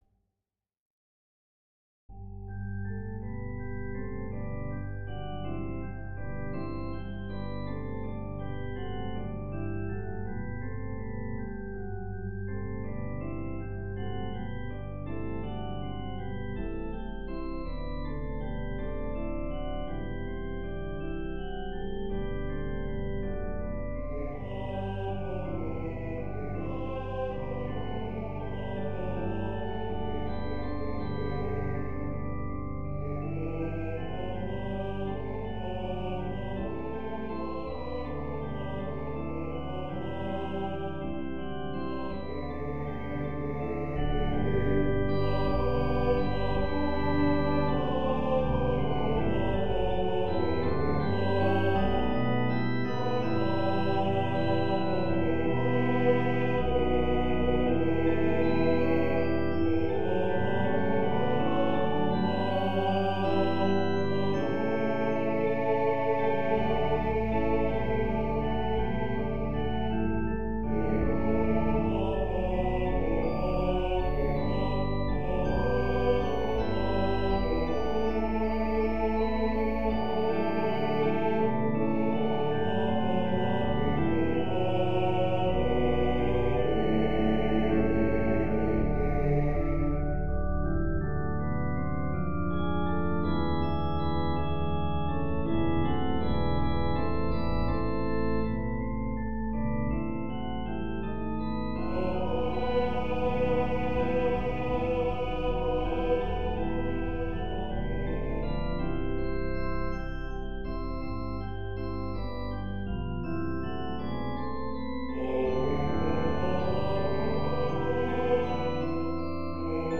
Score and Practice files for the singers:
Bass (entrance at 0:24)
howlovelyarethemessengers-bass.mp3